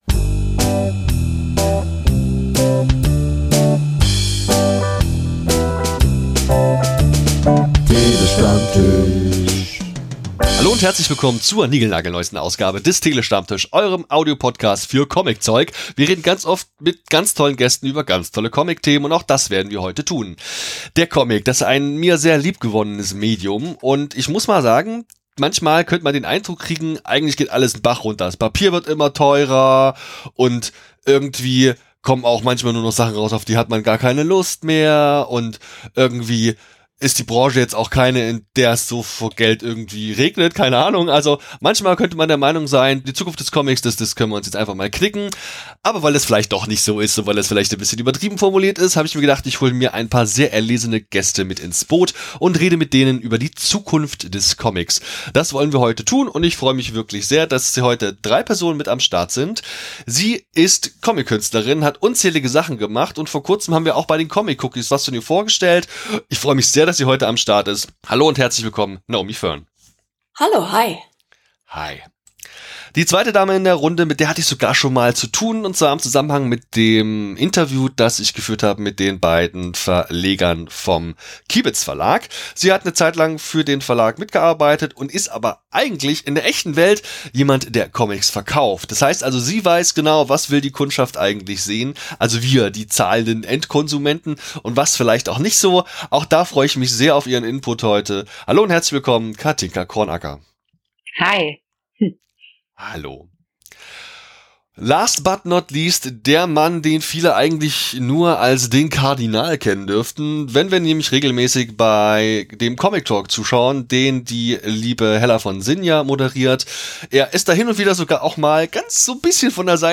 Short Crowd Cheer 2.flac